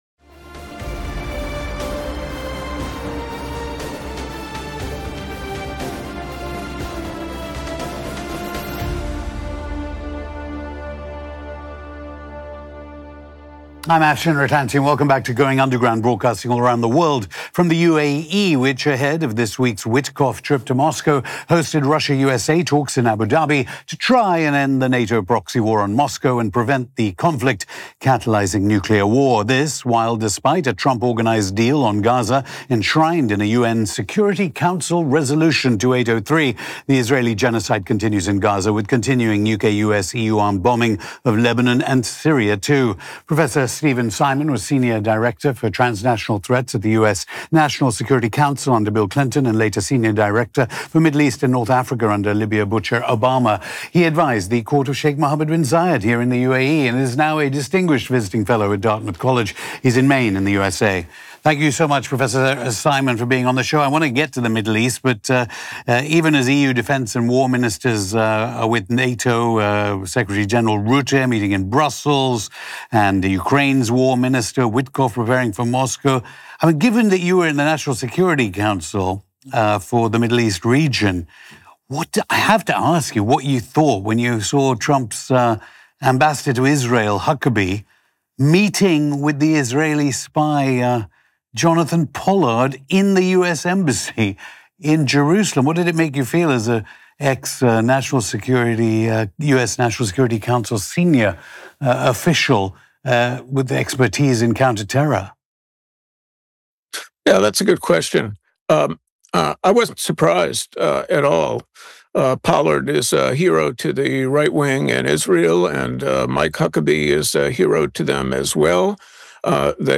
On this episode of Going Underground, we speak to Prof. Steven Simon, NSC Senior Director for Transnational Threats under President Bill Clinton and NSC Senior Director for Middle East and North Africa under President Barack Obama.
Going Underground Hosted by Afshin Rattansi Aftermath: The US’ Dirty War on Syria and Will US-Israel Attack Iran Again?